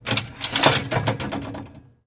Звуки турникета
Звук турникета на проходной